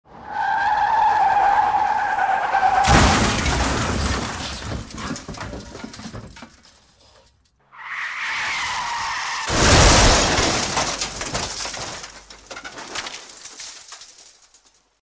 1. Визг тормозов и авария n2. Скрип тормозов и столкновение